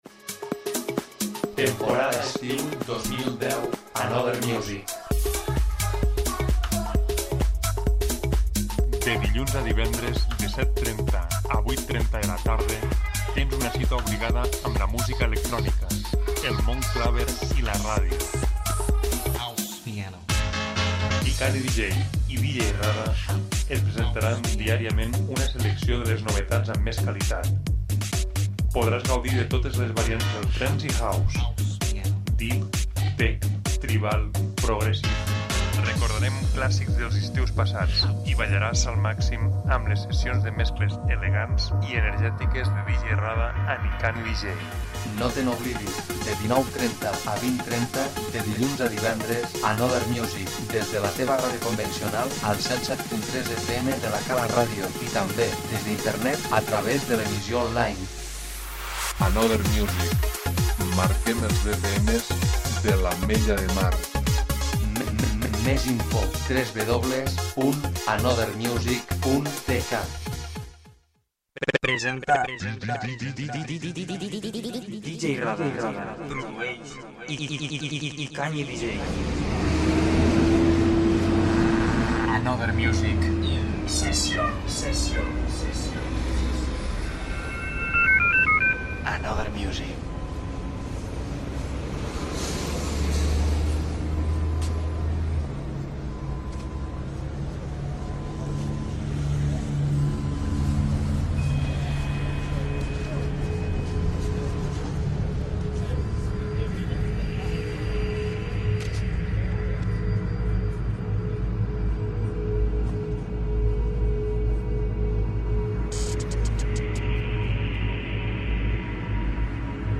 repassem més novetats de finals d'agost House i Trance.